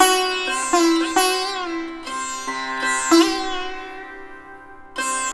100-SITAR3-L.wav